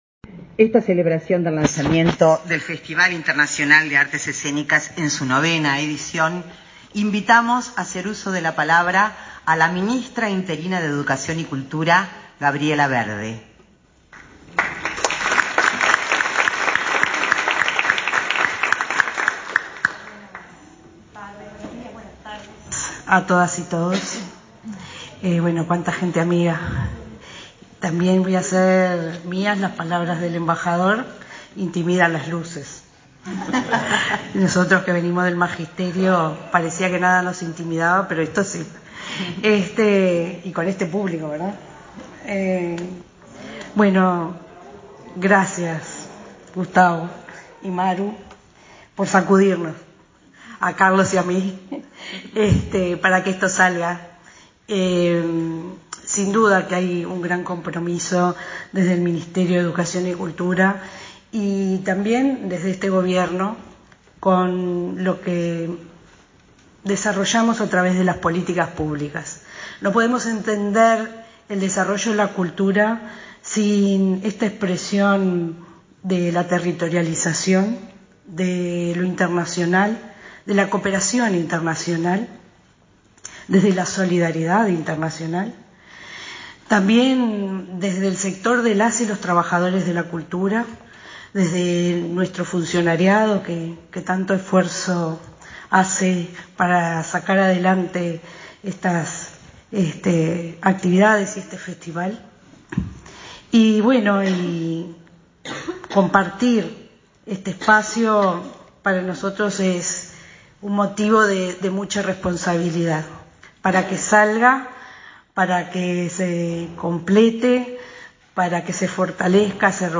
Palabras de la ministra interina de Educación y Cultura, Gabriela Verde
En el marco del lanzamiento de la novena edición del Festival Internacional de Artes Escénicas, se expresó la ministra interina de Educación y Cultura